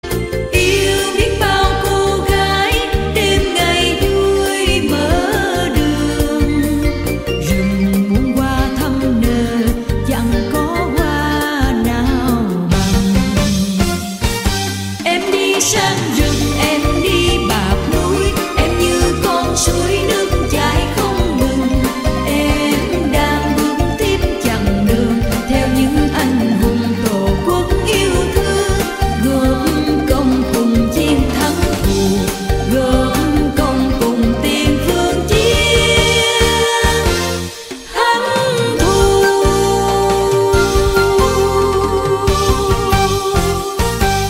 Nhạc Chuông Nhạc Vàng - Nhạc Đỏ